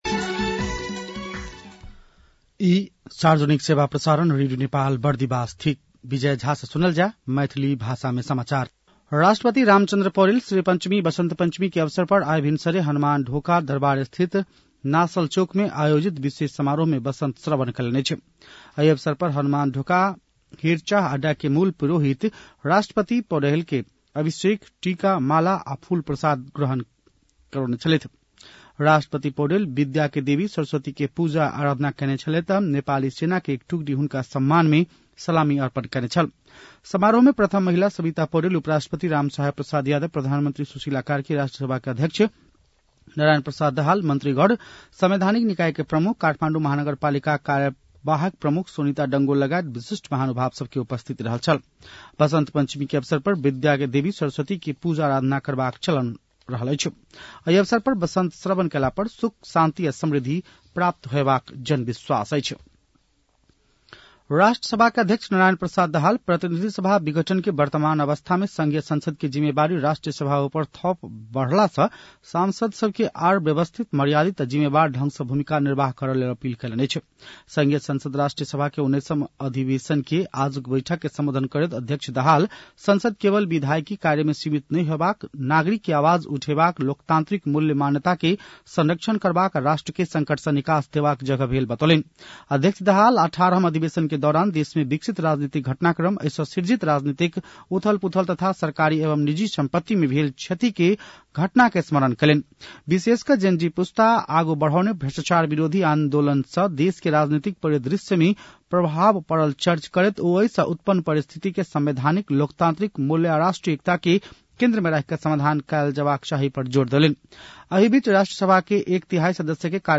मैथिली भाषामा समाचार : ९ माघ , २०८२
Maithali-news-10-09.mp3